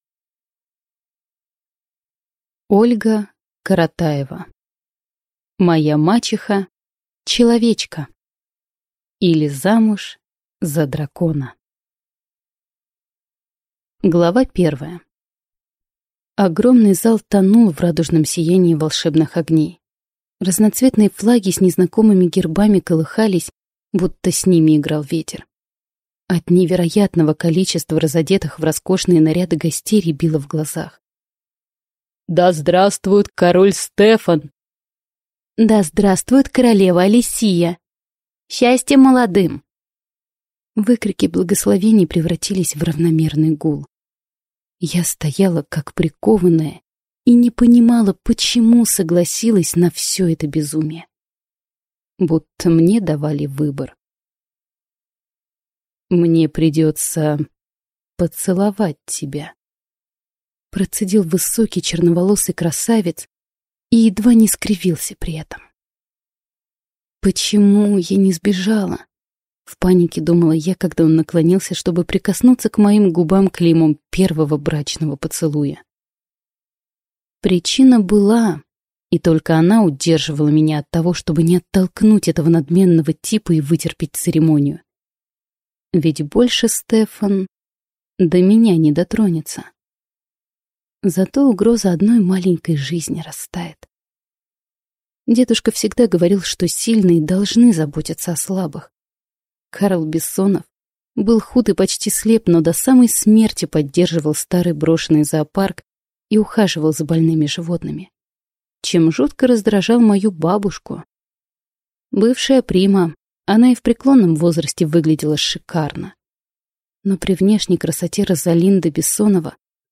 Аудиокнига Моя мачеха – человечка, или Замуж за дракона | Библиотека аудиокниг